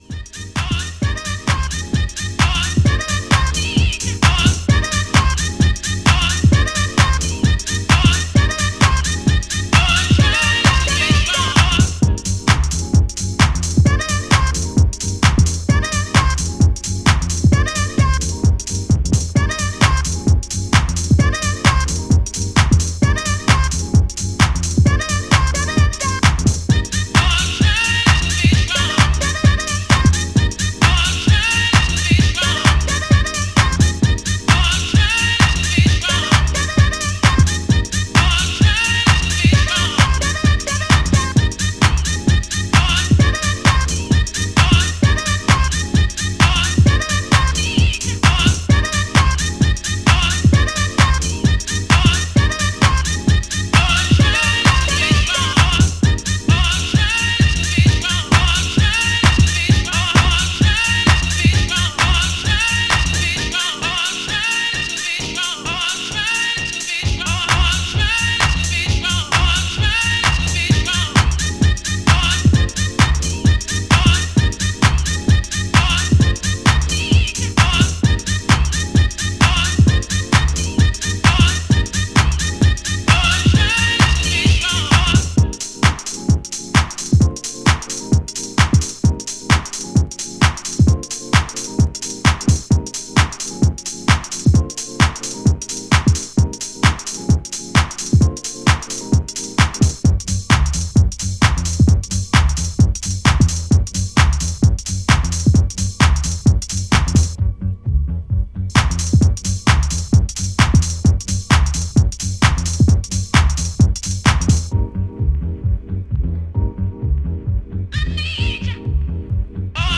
Repress of this Detroit underground house classic